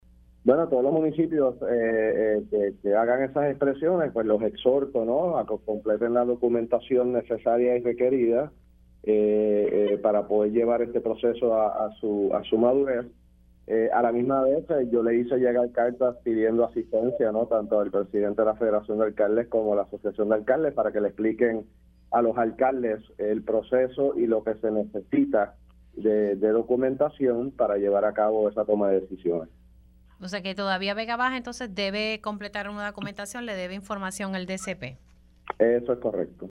El secretario de Seguridad Pública, General Arturo Garffer indicó en Pega’os en la Mañana que podría aumentar a unos 14 municipios con daños provocados por las lluvias torrenciales que han afectado a la isla desde el 19 de abril.